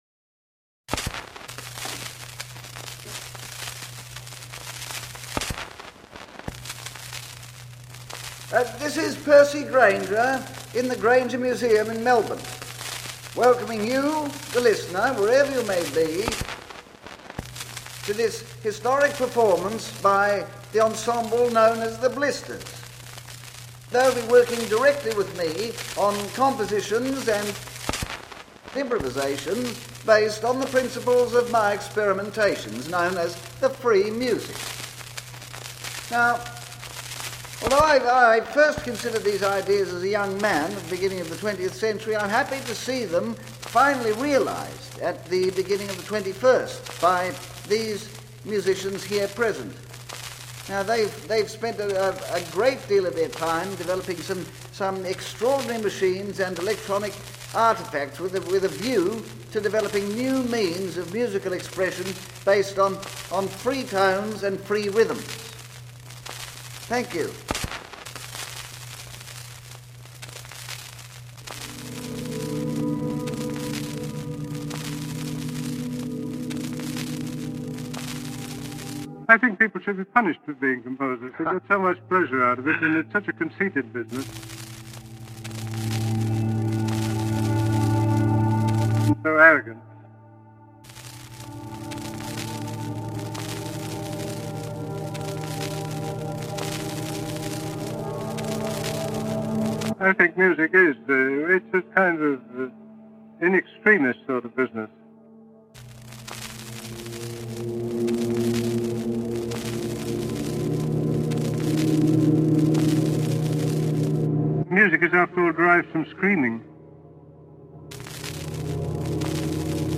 free rhythm, butterfly piano, player piano, radiophonic